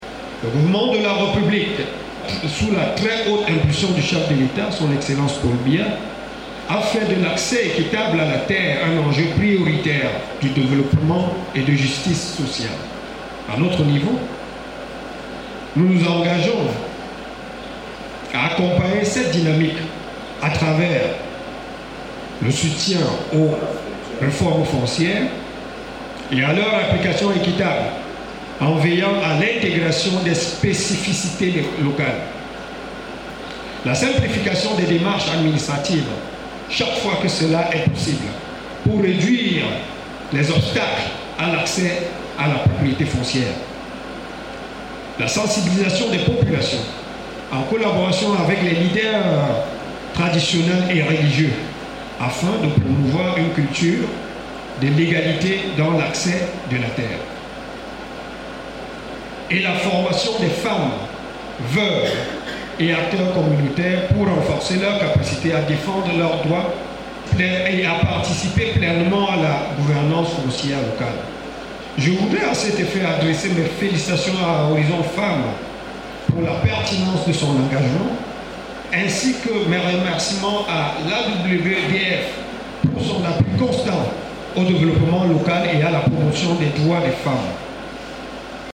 Ouvrant officiellement l’atelier et les échanges, le Représentant du Préfet du Mbam et Inoubou a salué les résultats palpables de ce projet ainsi que son impact positif, et qui se font ressentir depuis des mois sur le terrain.
Prenant la parole en lieu et place du Préfet, le 2eme Adjoint Préfectoral, Ali Dan Ladi a indiqué que la tenue de cet atelier départemental démontre à suffisance les enjeux et défis auxquels sont confrontés le Mbam et Inoubou en terme de défit fonciers persistants dans les communautés.
SON-EXTRAIT-DISCOURS-REP-PREFET-MBAM-INOUBOU.mp3